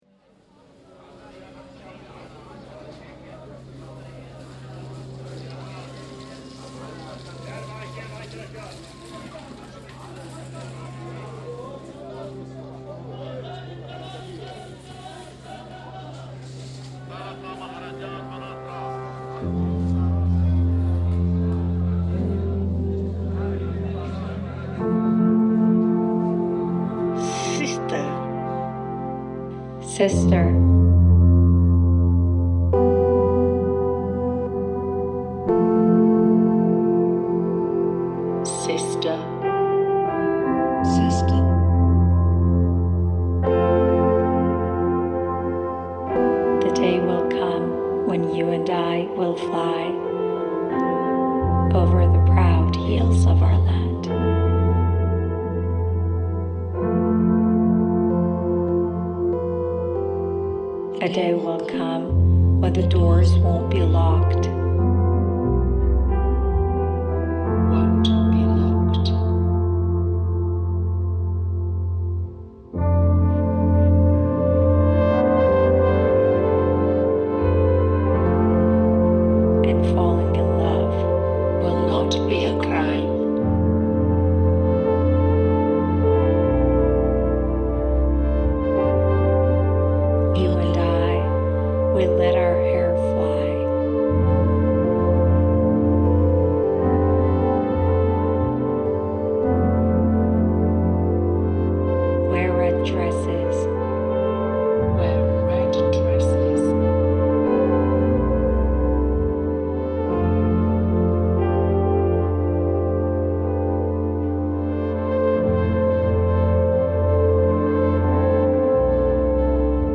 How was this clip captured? Ramallah market recording